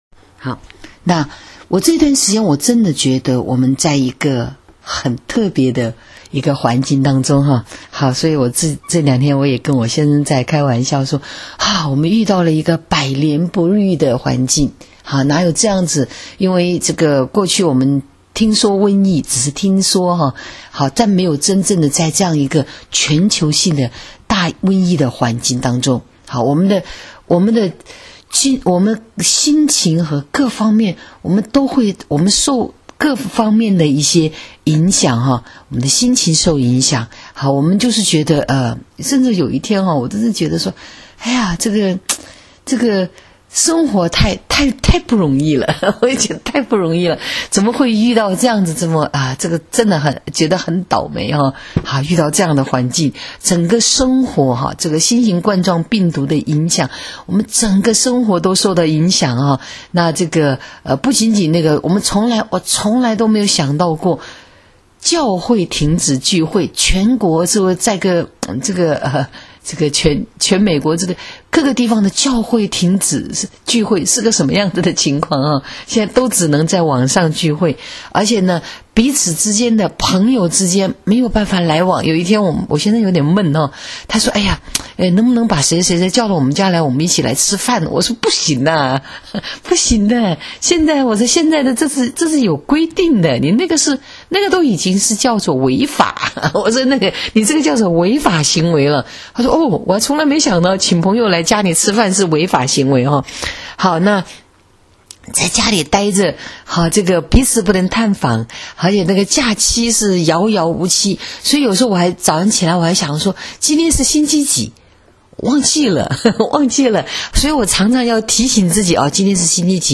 【主日信息】争战的时刻